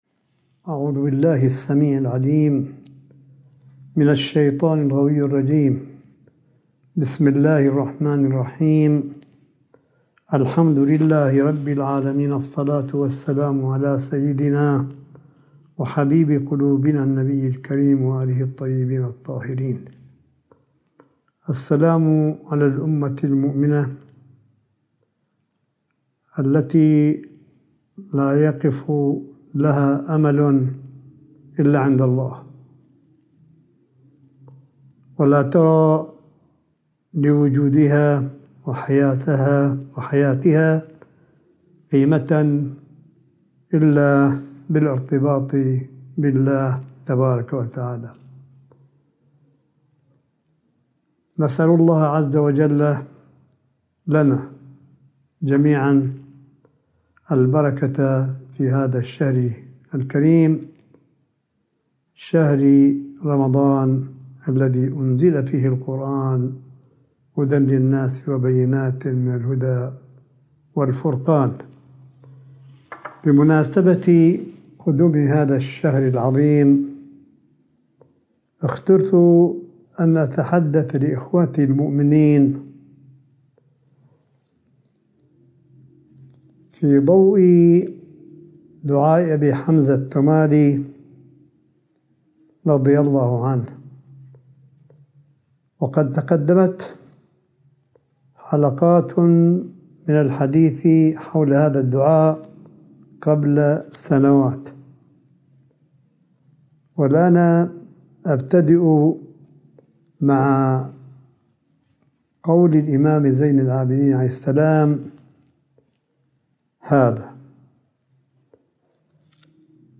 ملف صوتي للحديث الرمضاني (1) لسماحة آية الله الشيخ عيسى أحمد قاسم حفظه الله – 1 شهر رمضان 1442 هـ / 13 أبريل 2021م